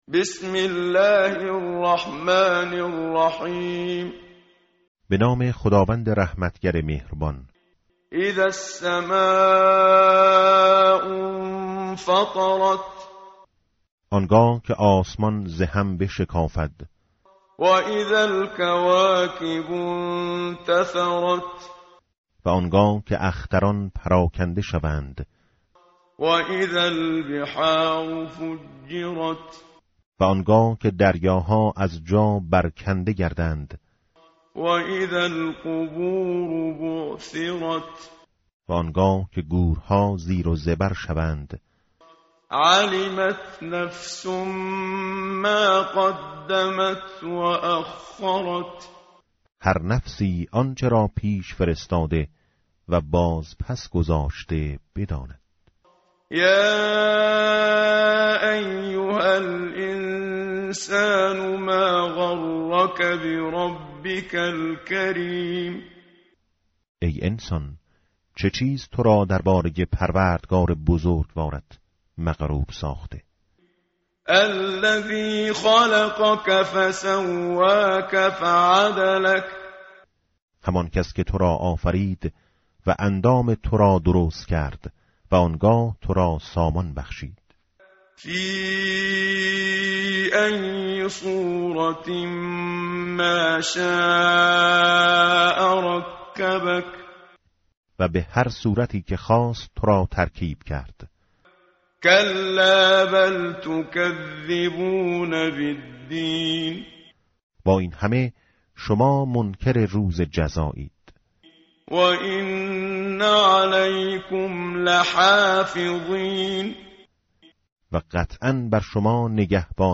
متن قرآن همراه باتلاوت قرآن و ترجمه
tartil_menshavi va tarjome_Page_587.mp3